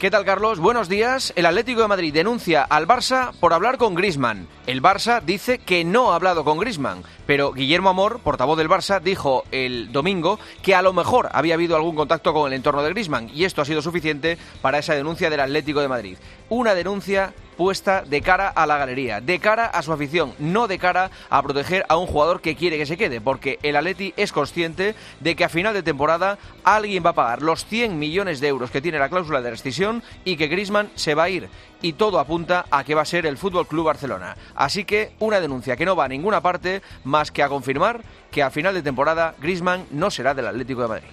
El comentario de Juanma Castaño
la denuncia que el Atlético de Madrid ha puesto al Barcelona ante la FIFA por ponerse en contatco con Griezmann para un posible fichaje del club azulgrana, en el comentario deportuivo de Juanma Castaño en 'Herrera en COPE'